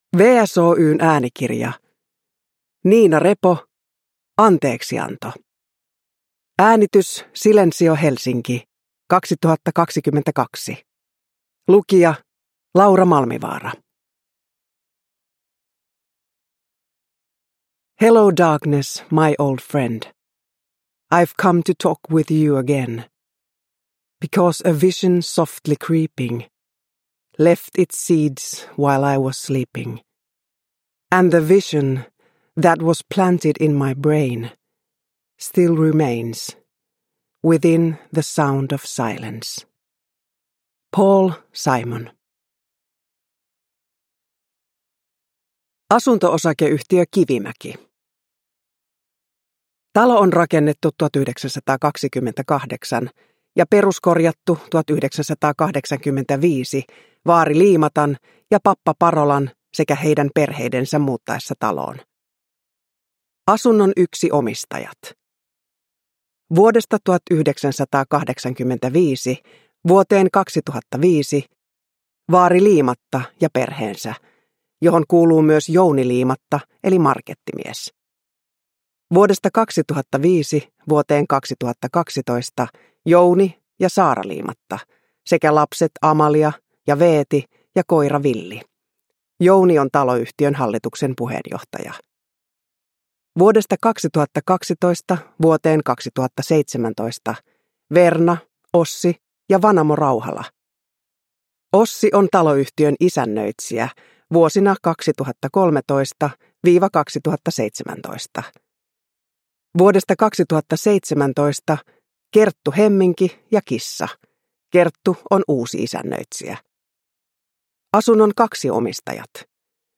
Anteeksianto – Ljudbok – Laddas ner
Uppläsare: Laura Malmivaara